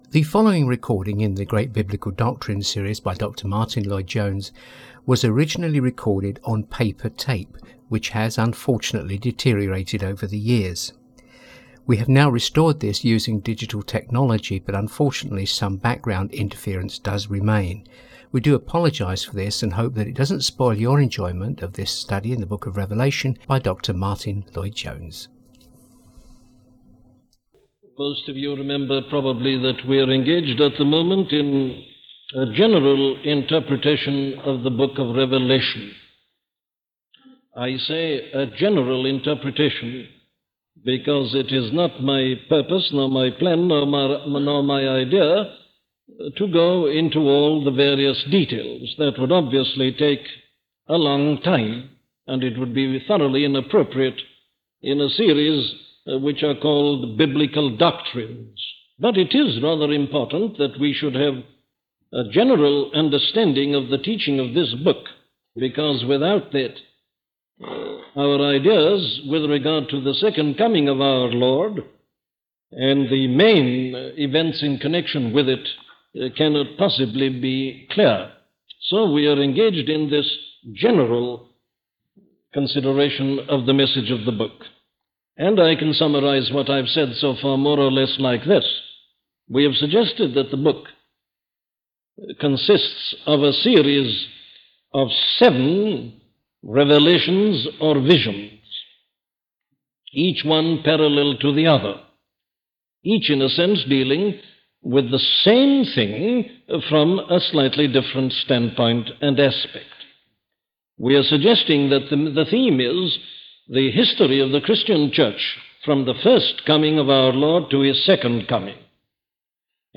The Trumpets: A sermon
The Trumpets - a sermon from Dr. Martyn Lloyd Jones